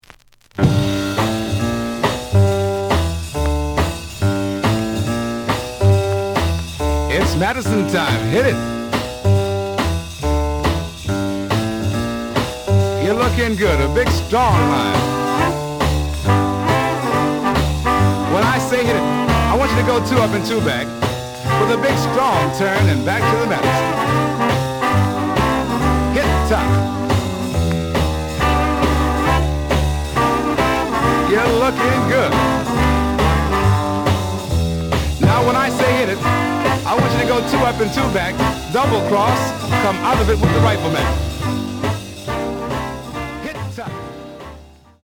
The audio sample is recorded from the actual item.
●Genre: Jazz Other